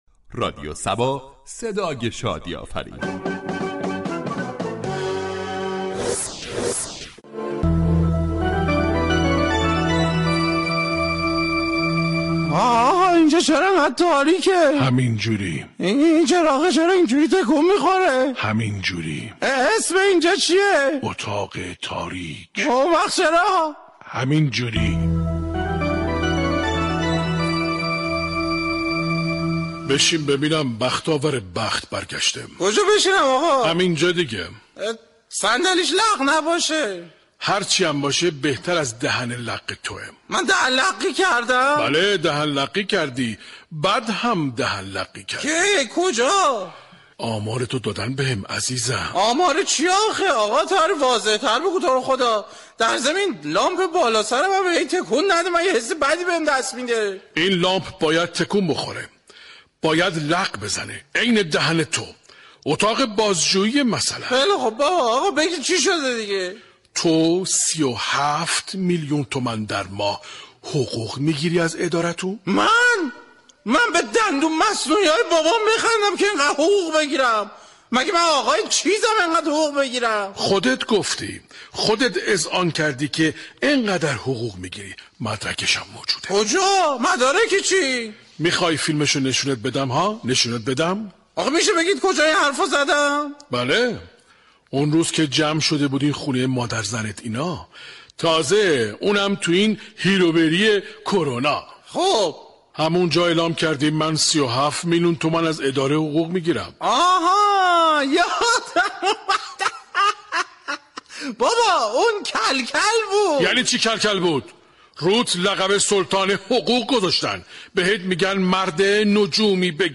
در بخش نمایشی شهر فرنگ با بیان طنز به موضوع بحقوق های نجومی پرداخته شده است ،در ادامه شنونده این بخش باشید.